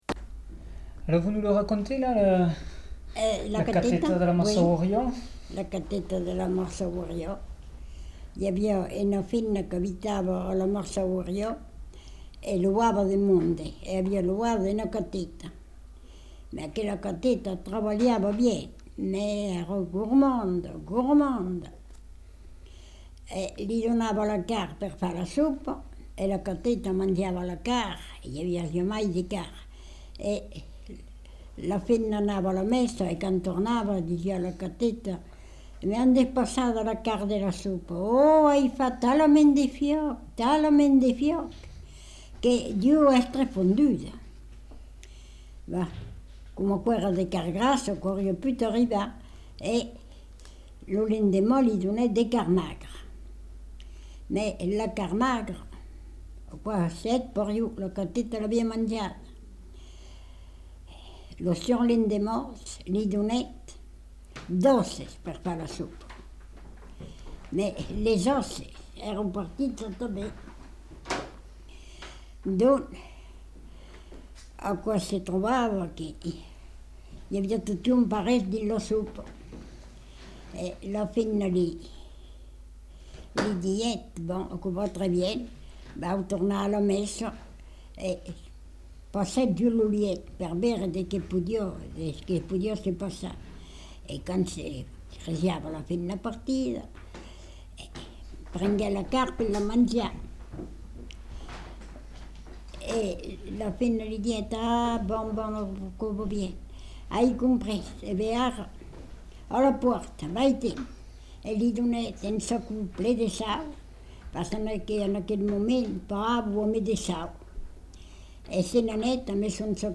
Lieu : Tournay
Genre : conte-légende-récit
Effectif : 1
Type de voix : voix de femme
Production du son : parlé